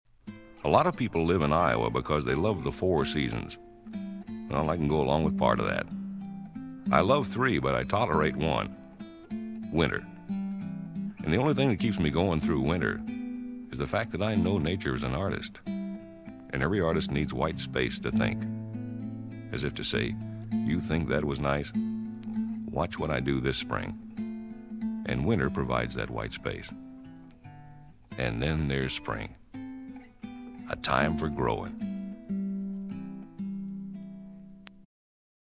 This folk music
drums
piano
bass guitar
harmonica
banjo
strings
vocals
Folk music--Iowa